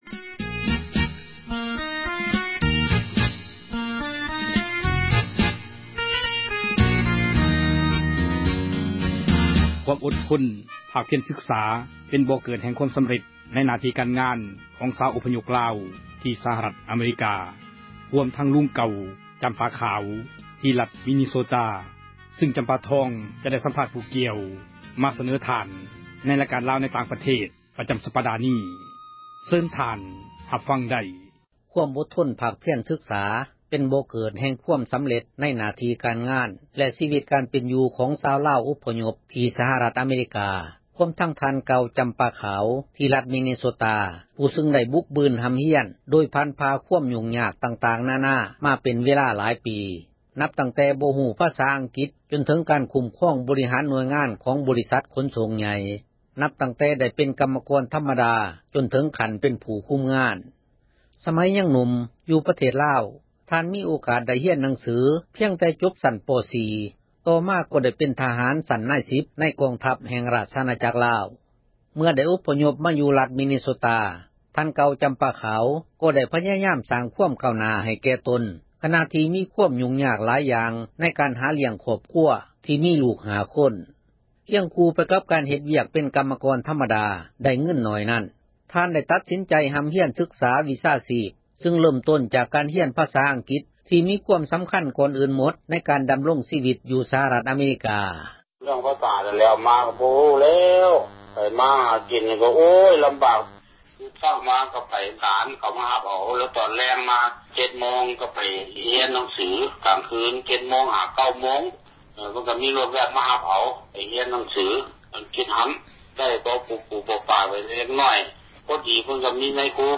ໄດ້ສໍາພາດ